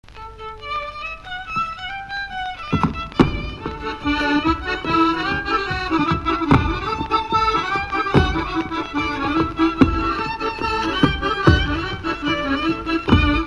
Courante
Résumé Instrumental
danse : branle : courante, maraîchine
Enquête Arexcpo en Vendée
Pièce musicale inédite